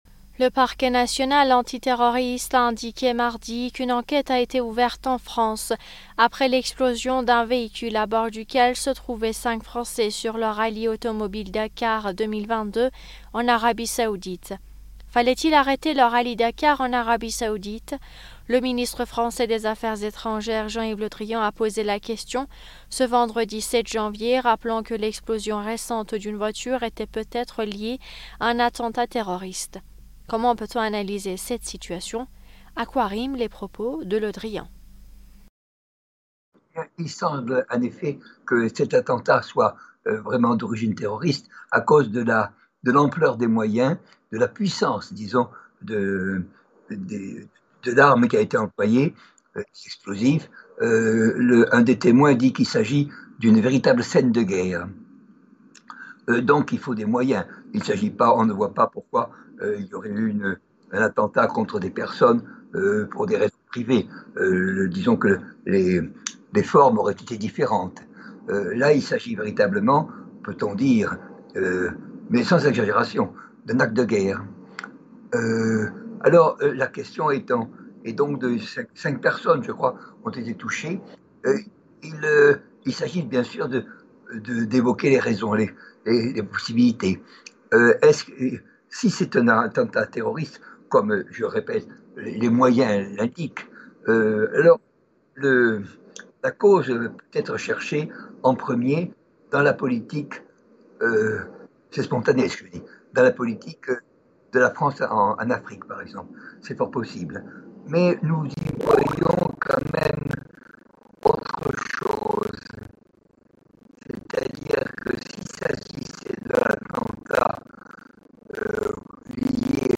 Mots clés France Arabie saoudie interview Eléments connexes Reconnaissance de la Palestine par Macron : réalisme ou geste symbolique ?